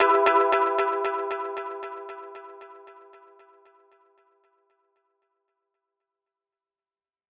Echoes_E_01.wav